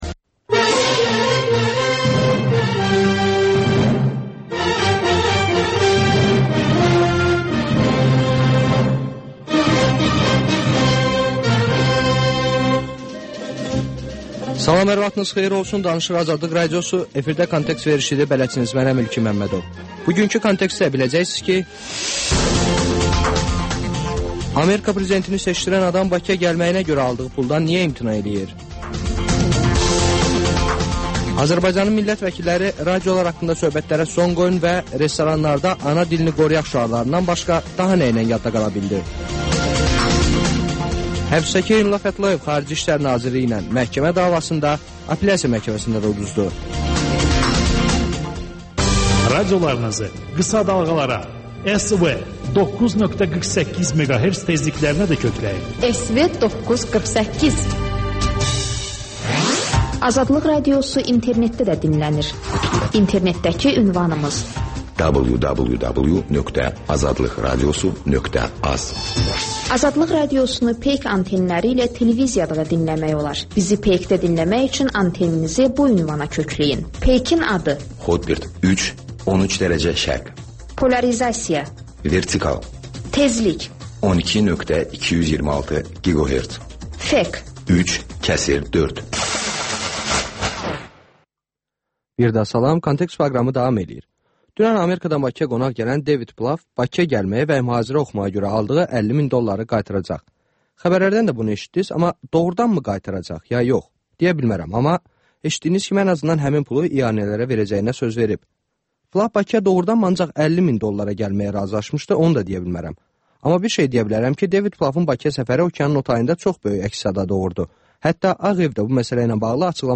Müsahibələr, hadisələrin müzakirəsi, təhlillər Təkrar